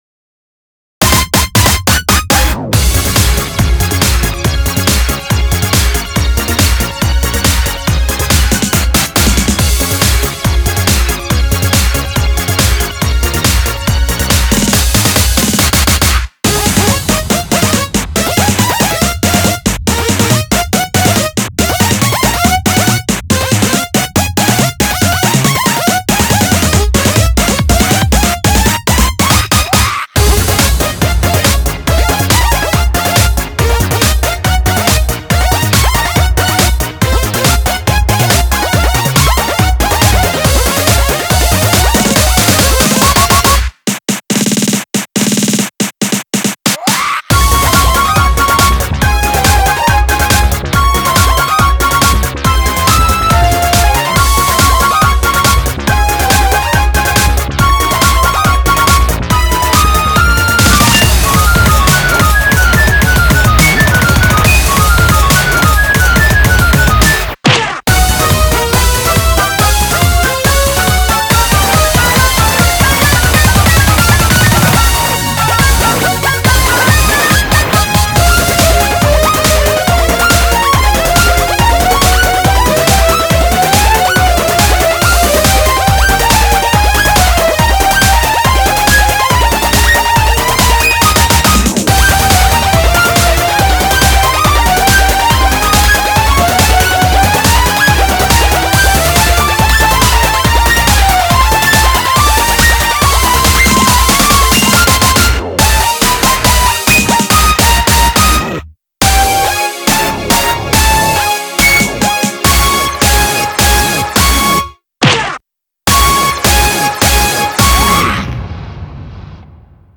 BPM140